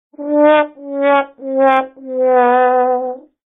Sad Trombone